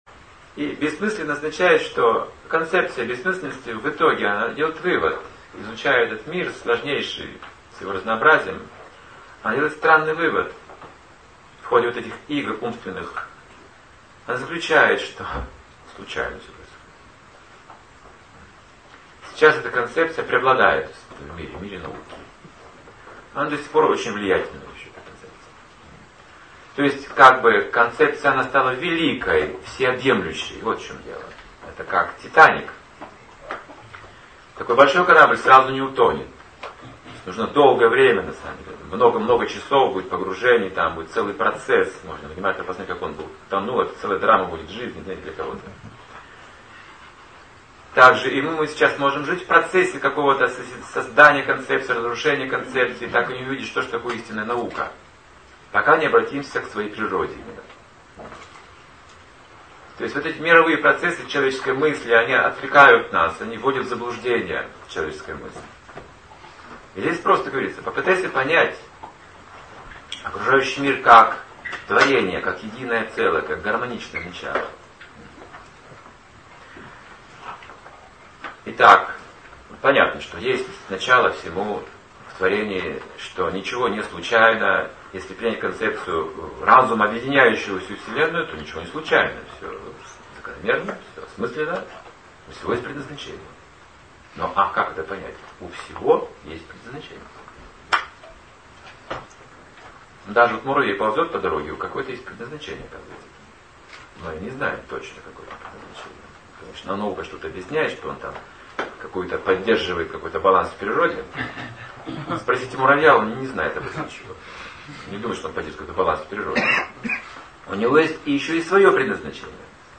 Темы, затронутые в лекции: Отношения преданных Смирение Дружеское расположение Истории о Прабхупаде Качество дружбы Отеческие взаимоотошения Препятствия в отношениях Терпение Свобода выбора